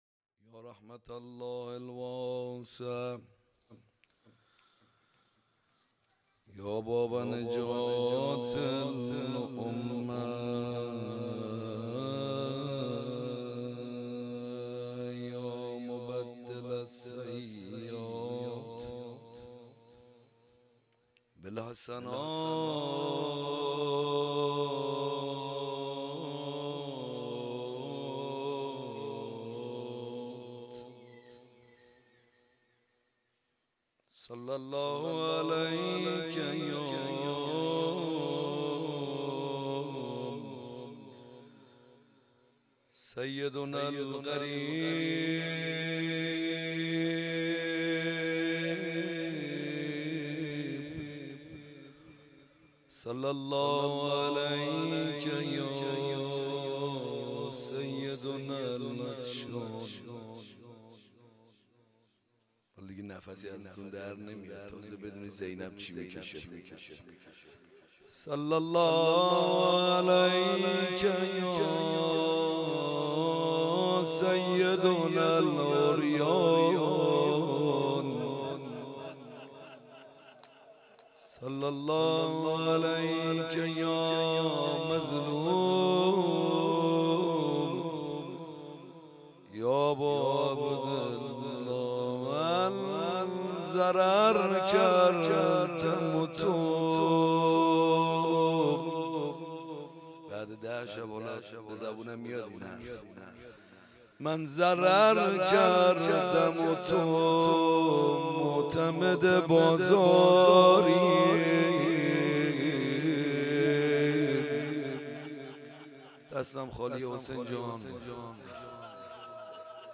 حسینیه کربلا